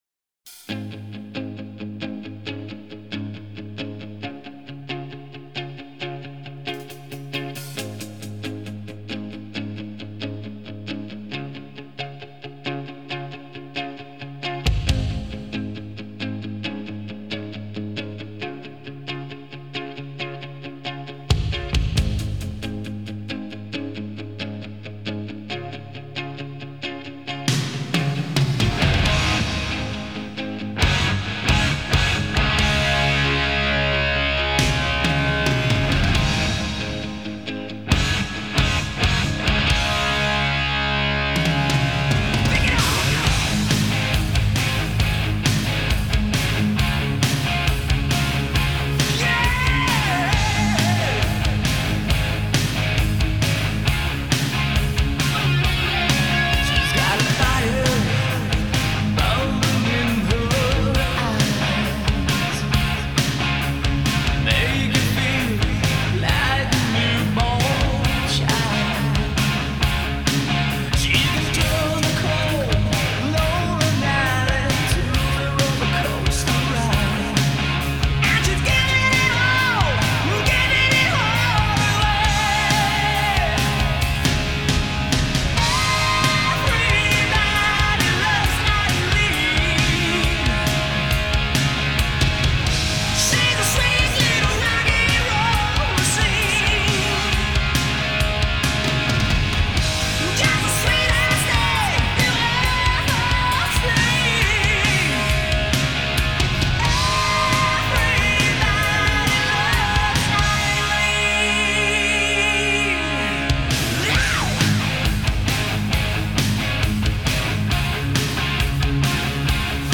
американская глэм-метал-группа